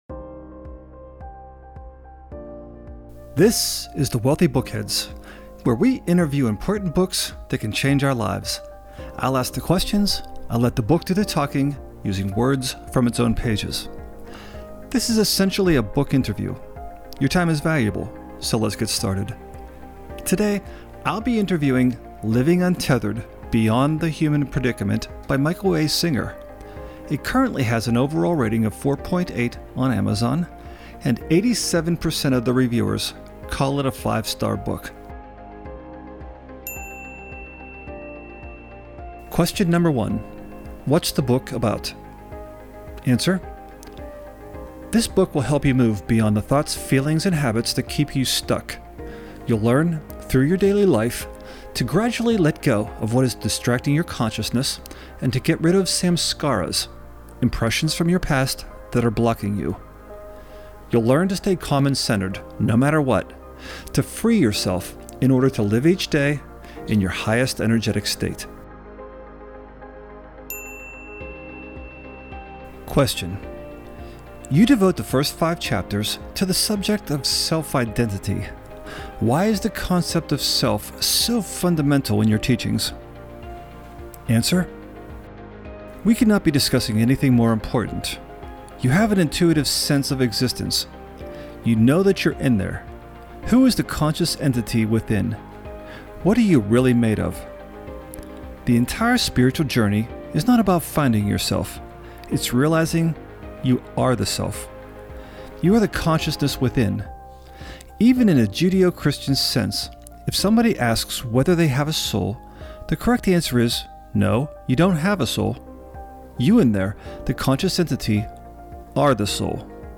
The Wealthy Bookheads Interview